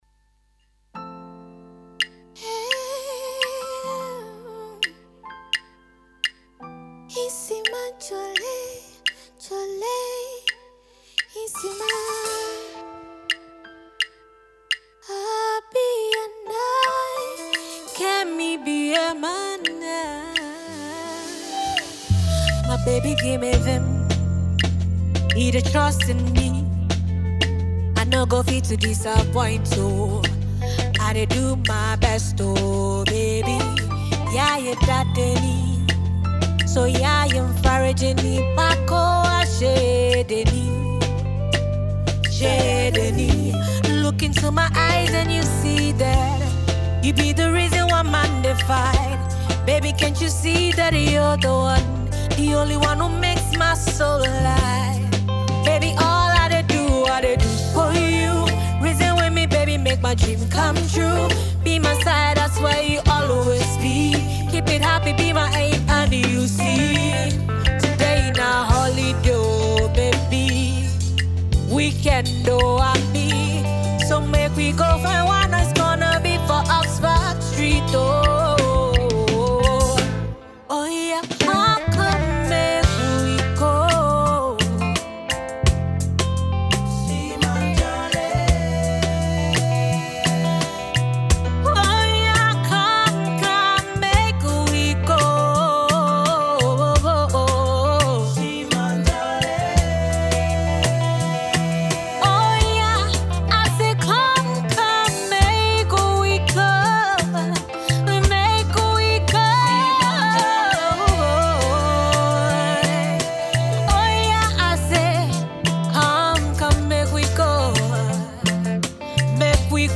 and this is a live performance for fans and music lovers.